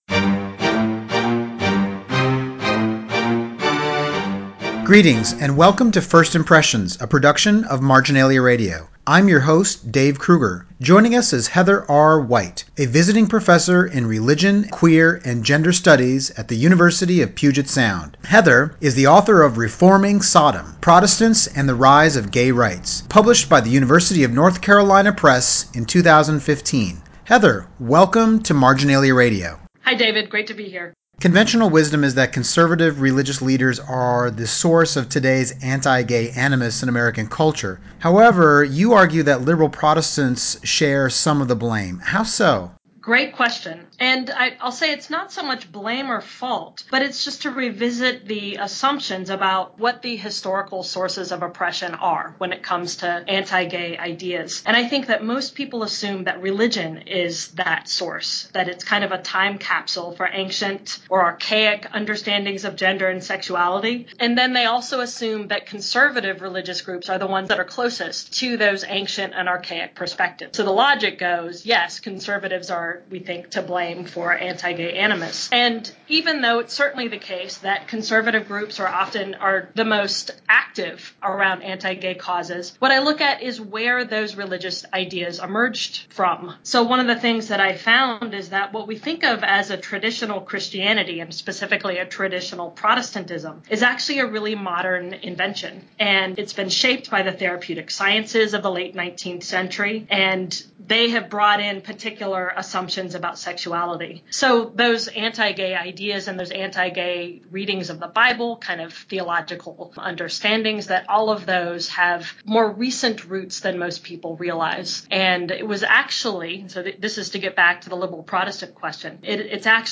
This interview was originally released as Episode #45 of MRB Radio’s First Impressions program on October 20, 2015.